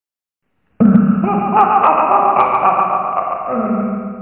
Evil laughing
Evil laugh Laughing Low Scary Spooky sound effect free sound royalty free Funny